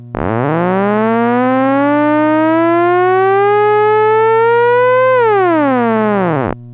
Theremin
This is the raw Volume wave shape at Out-2B (Also visit Pitch Wave)
This wave shape sample is taken from the Out-2B  TRS 3.5 mm stereo jack on the Phoenix Volume Control board. It will not sound good but we are only interested in the wave shape.
Ugly but perfect for Volume Control
volume-wave.mp3